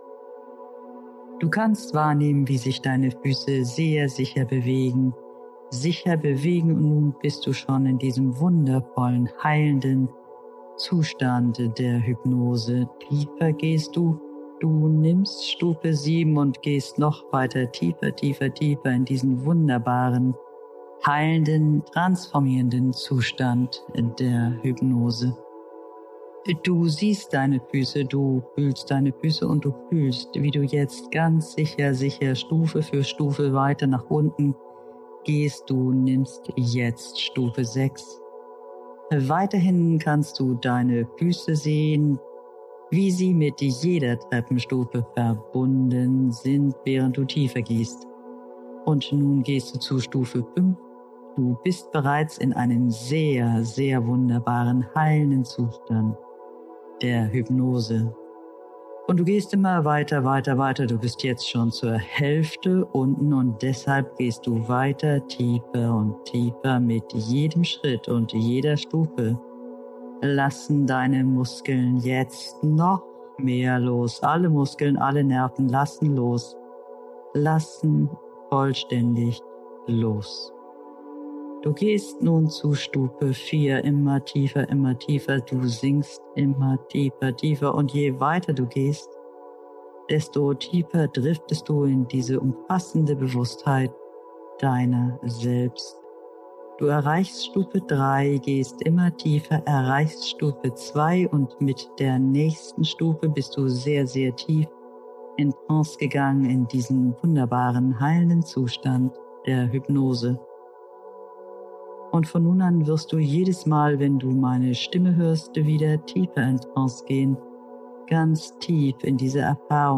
Meditation zum Buch:
Beziehung-Musik-R3-PS.wav